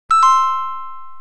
Error.aac